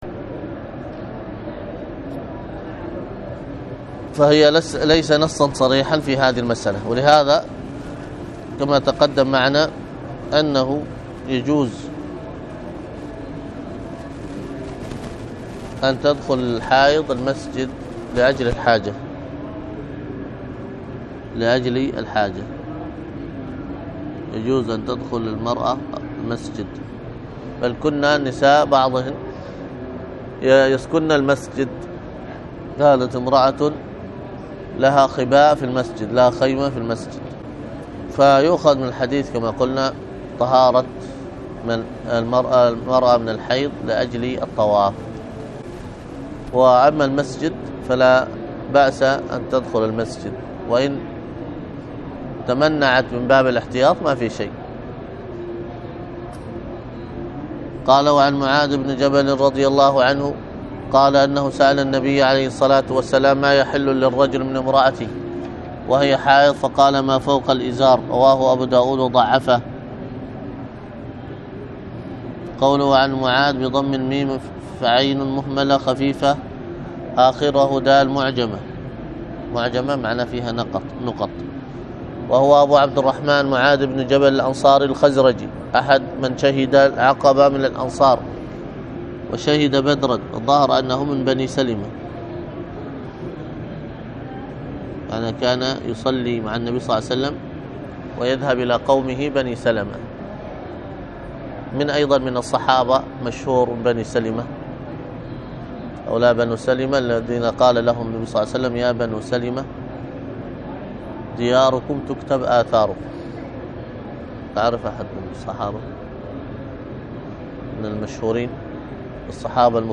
الدرس في كتاب الطهارة 72